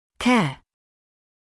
[keə][кэа]лечение; уход (за больным)